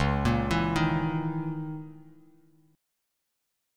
C#m6add9 chord